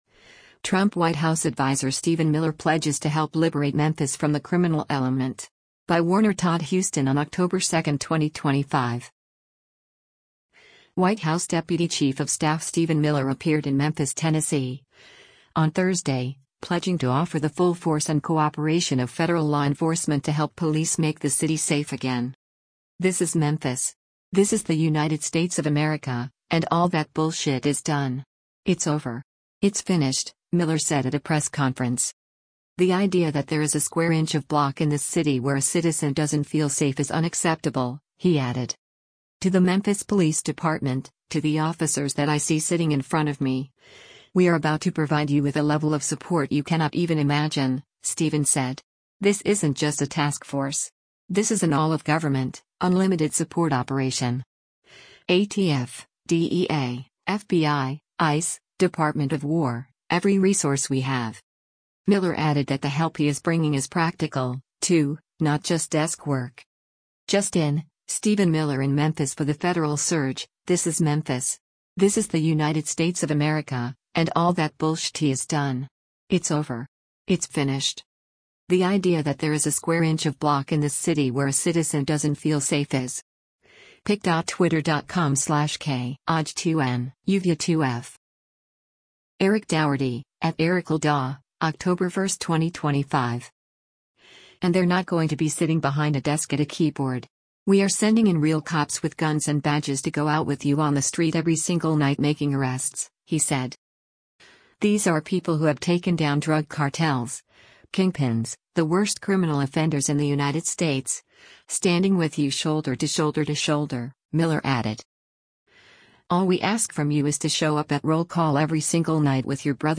“This is Memphis. This is the United States of America — and all that bullshit is done! It’s over. It’s finished,” Miller said at a press conference.